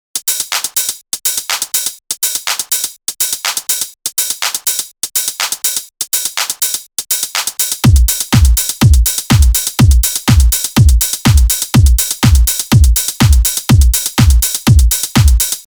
typischer house beat/groove
Hihi, diesen Groove hab ich auch irgendwann mal nachgebaut und dachte anfangs auch erst die closed Hihats haben die falsch gesetzt Damit klappts: 1. closed/open HH müssen ineinander übergreifen (ruhig mal verschiedene Samples probieren). 2. Das Tempo muss relativ langsam sein (z.B. 122 BPM), ansonsten groovt es nicht.